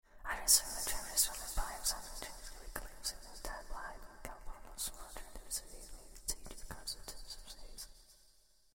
Звуки внутреннего голоса
Женщину тревожит шепот внутреннего голоса